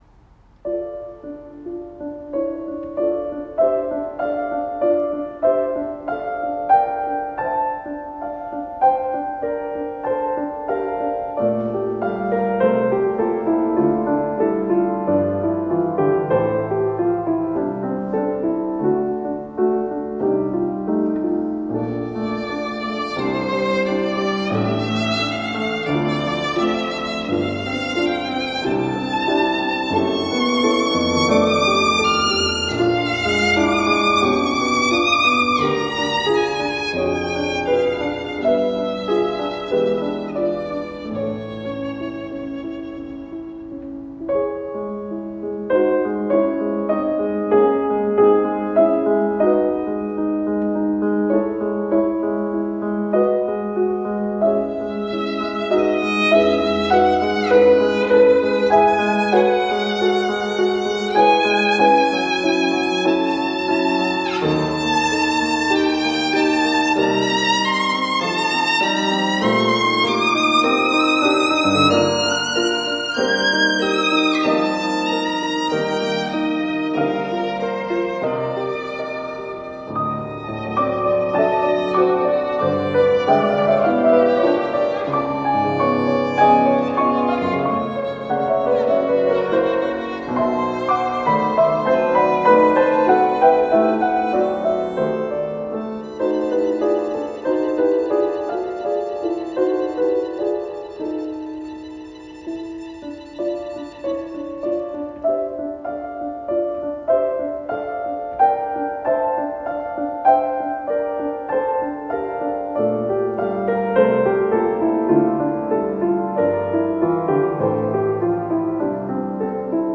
CHAMBER MUSIC ENSEMBLE
While it is a technically difficult piece, it is rewarding in its execution as the diverse harmonies supported by beautiful melodies in each movement inspire both listener and performer alike. We chose to record the second movement, Adagietto, which evokes contemplative emotion and lyrical grace.